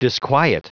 Prononciation du mot disquiet en anglais (fichier audio)
Prononciation du mot : disquiet